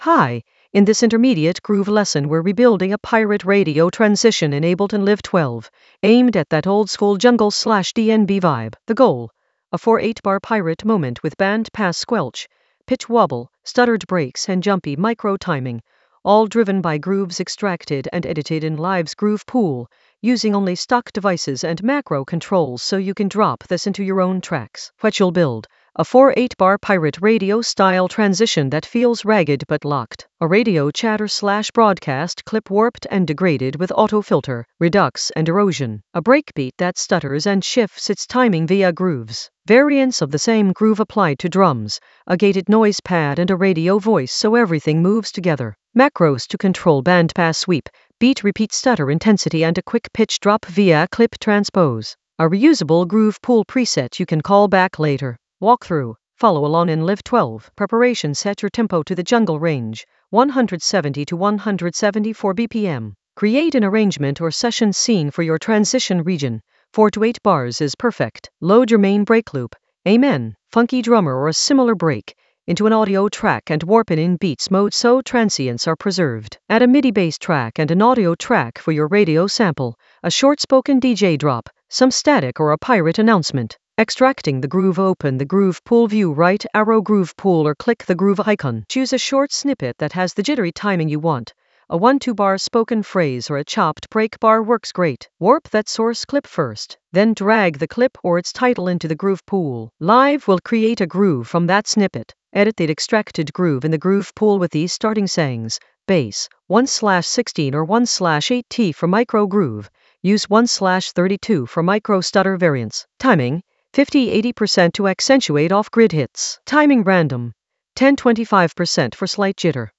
An AI-generated intermediate Ableton lesson focused on Rebuild a pirate-radio transition using groove pool tricks in Ableton Live 12 for jungle oldskool DnB vibes in the Groove area of drum and bass production.
Narrated lesson audio
The voice track includes the tutorial plus extra teacher commentary.